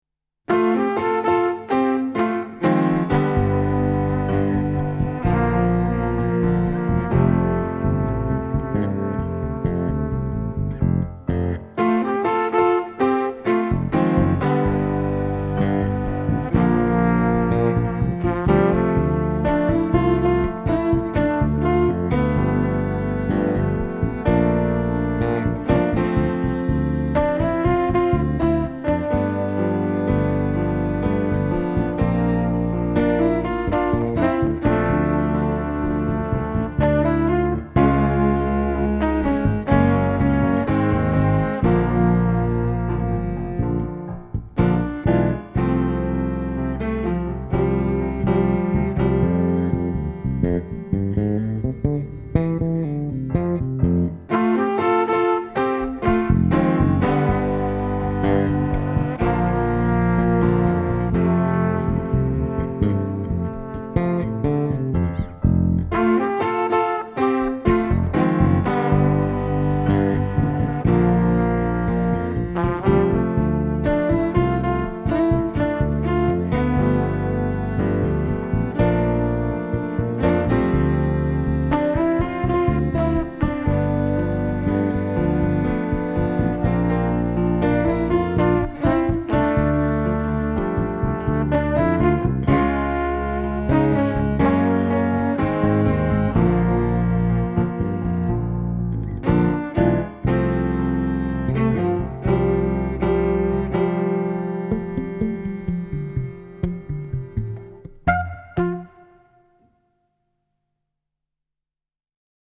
Schlagzeuglos glücklich